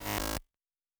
pgs/Assets/Audio/Sci-Fi Sounds/Electric/Glitch 1_08.wav at master
Glitch 1_08.wav